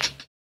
HiHat (Go With The Flow).wav